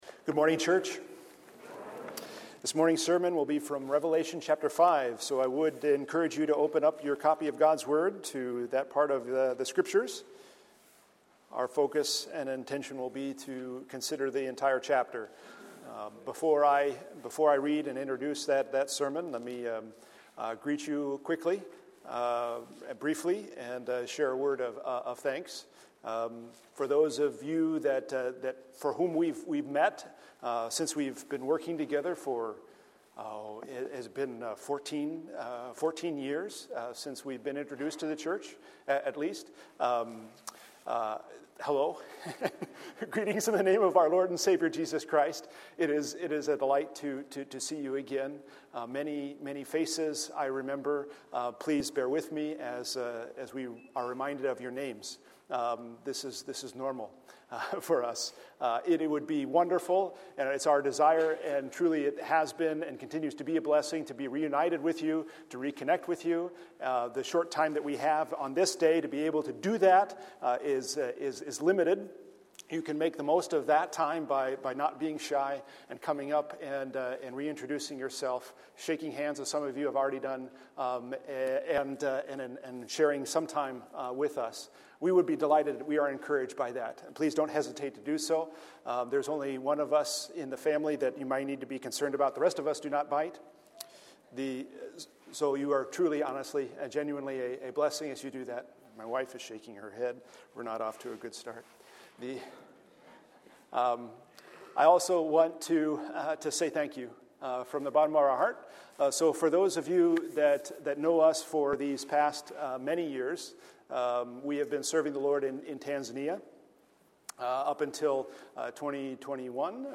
A sermon from the series "Missions Emphasis."